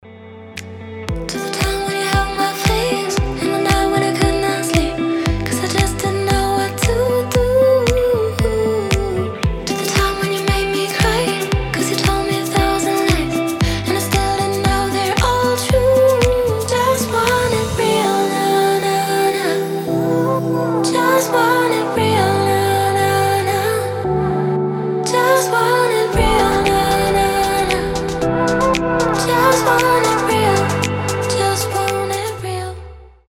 • Качество: 320, Stereo
deep house
мелодичные
спокойные
chillout
красивый женский голос
нежные
Очень приятные музыка и вокал